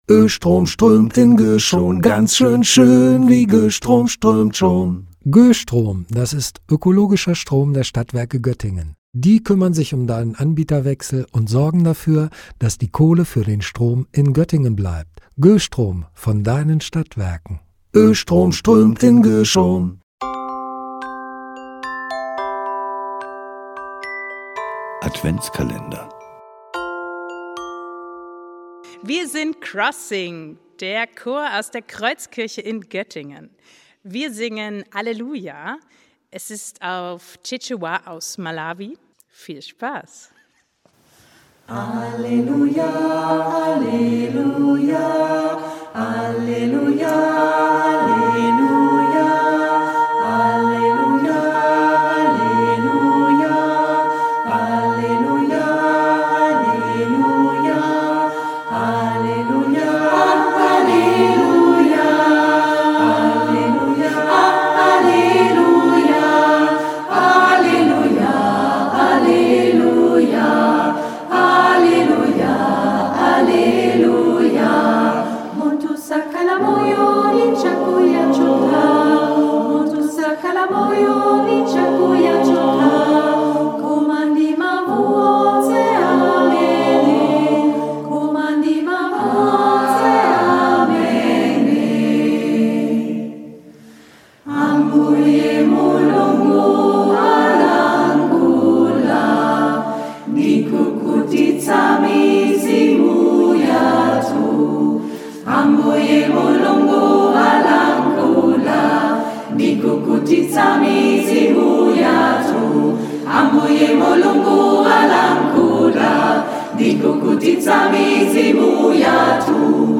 Türchen 22: Gospel- und Pop-Chor „Crossing“